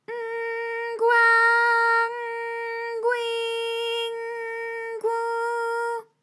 ALYS-DB-001-JPN - First Japanese UTAU vocal library of ALYS.
gw_N_gwa_N_gwi_N_gwu.wav